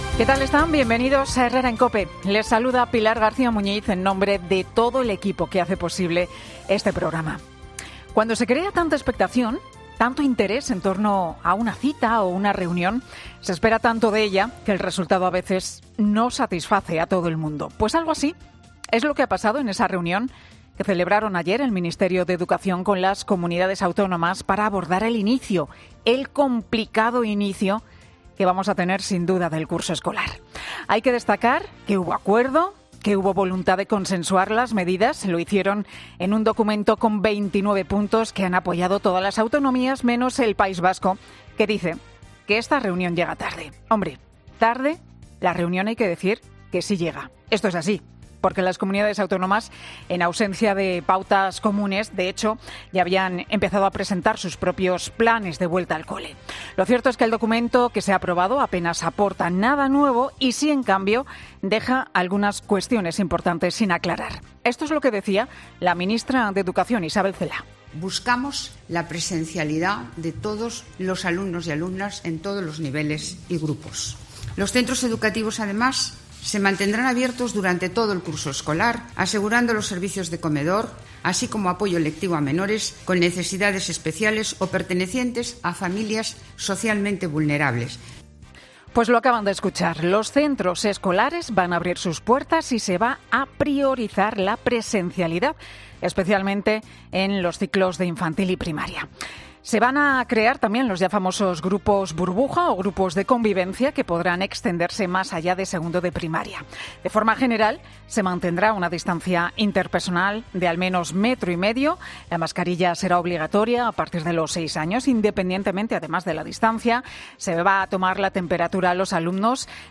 [ESCUCHA AQUÍ EL MONÓLOGO DE LAS 8 DE "HERRERA EN COPE"]